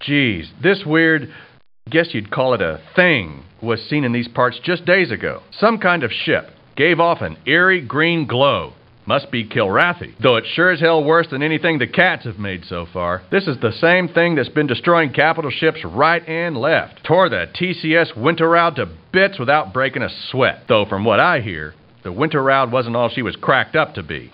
Bartender_Rumor_20_Male.mp3